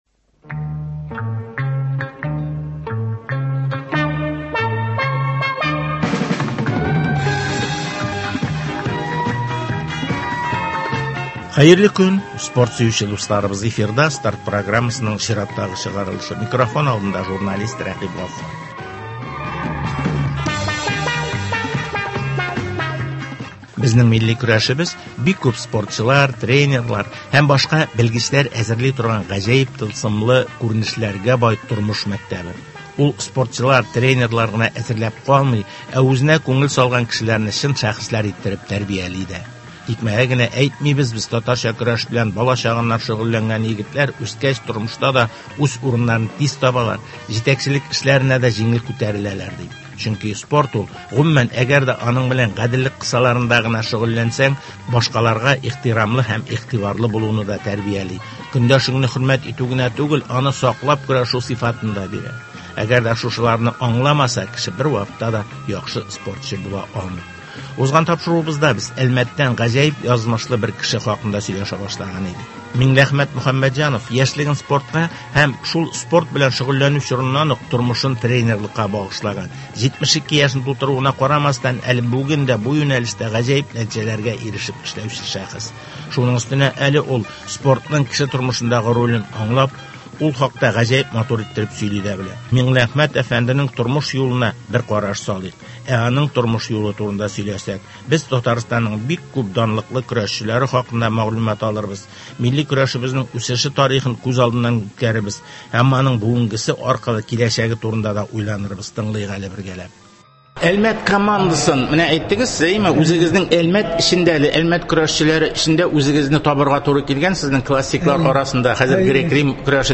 Казан – Россиянең спорт башкаласы, авылда спортның үсеше, дөнья күләмендәге чемпионатларга әзерләнү, районнар масштабындагы ярышларны үткәрү – әлеге һәм башка темалар хакында спортчылар, җәмәгать эшлеклеләре һәм спорт өлкәсендәге белгечләр белән әңгәмәләр.